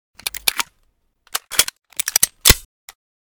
pp2000_reload_empty.ogg